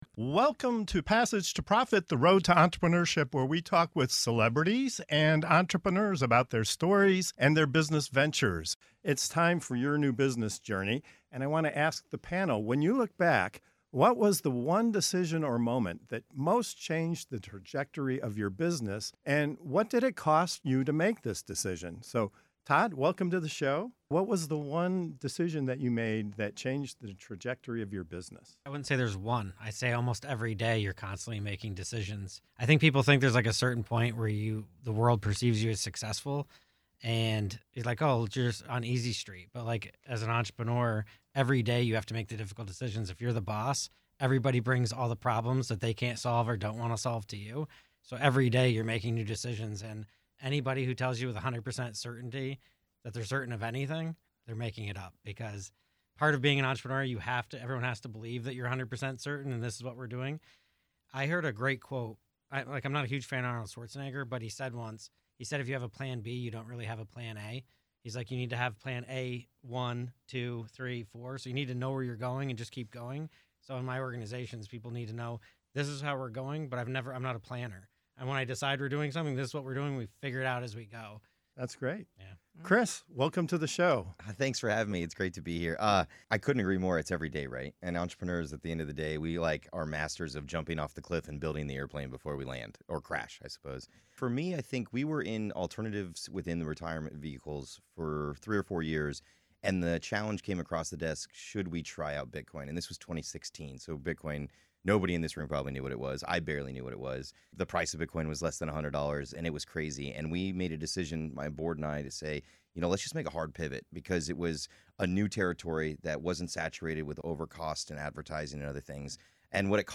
What’s the one decision that can change the entire trajectory of a business — and what does it really cost? In this powerful segment of "Your New Business Journey" on Passage to Profit Show, seasoned entrepreneurs reveal the bold pivots, high-stakes risks, and uncomfortable leadership moments that transformed their companies.